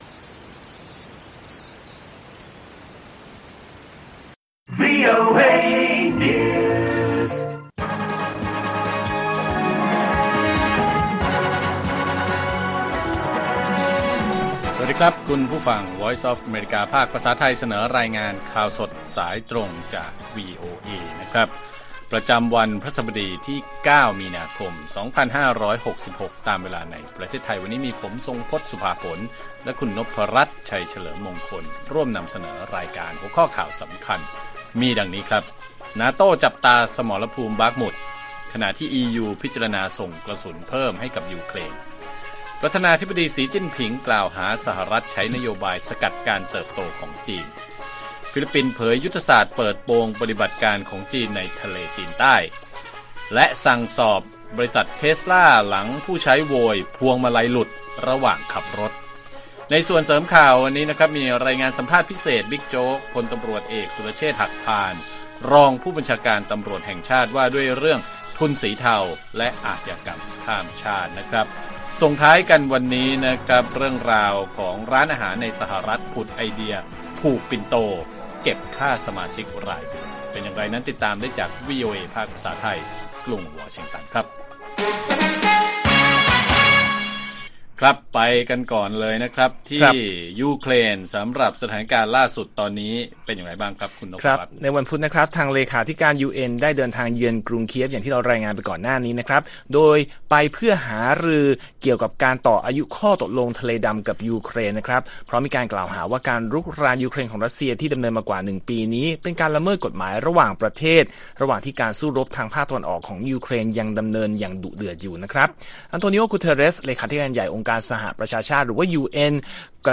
ข่าวสดสายตรงจากวีโอเอ ไทย พฤหัสฯ 9 มีนาคม 2566